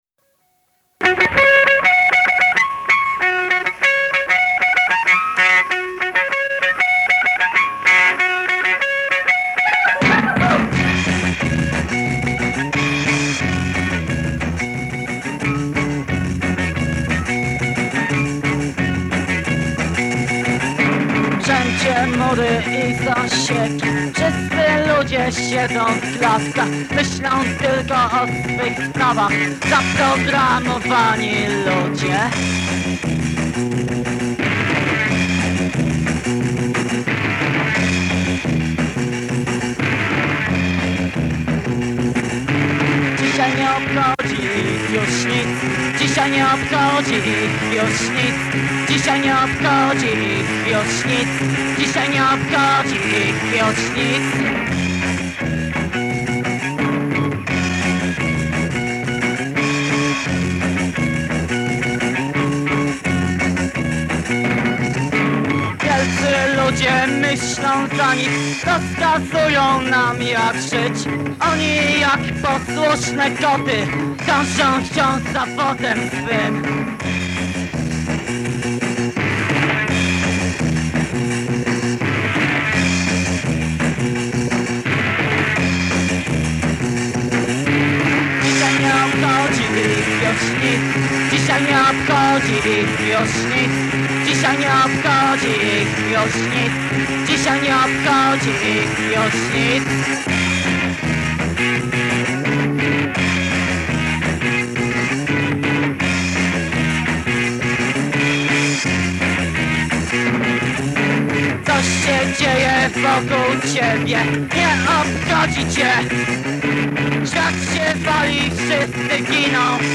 gitara basowa
perkusja głos Przez lata dość pilnie strzeżone nagrania.